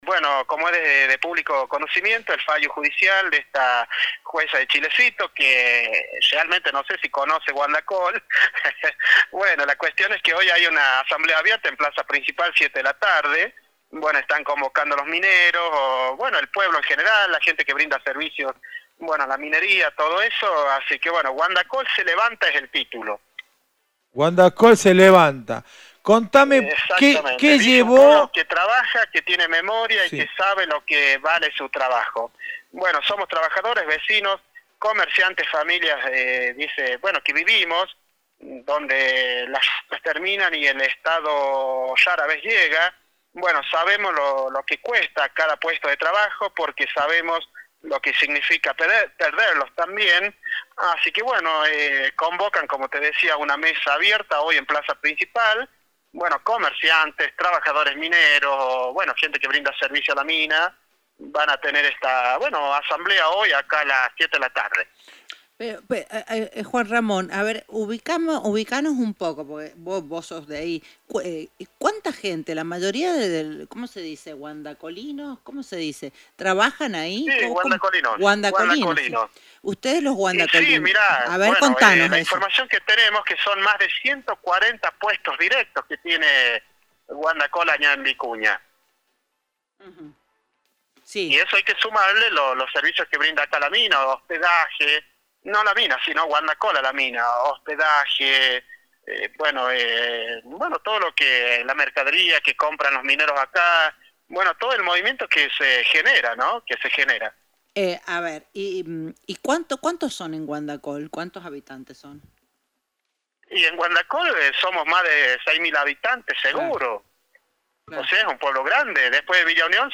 COMUNIDAD ORGANIZADA. GUANDACOL SE LEVANTA.